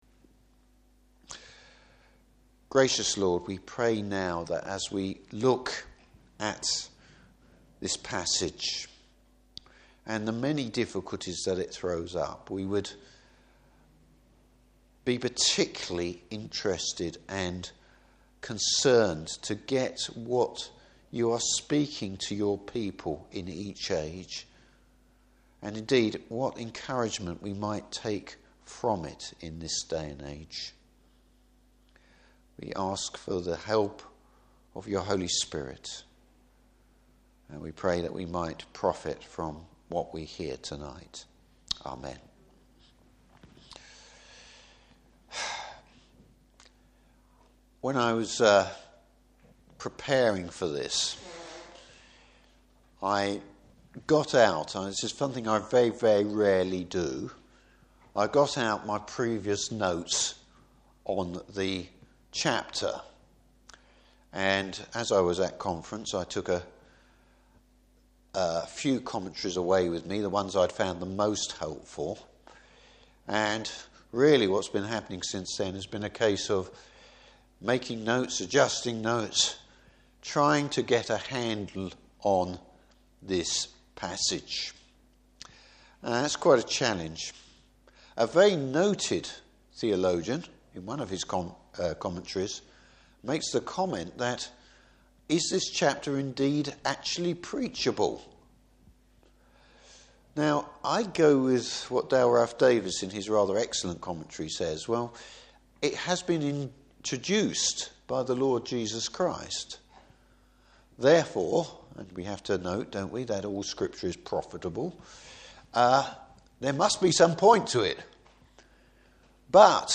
Service Type: Evening Service History belongs to the Lord! Topics: God's actions behind the scenes.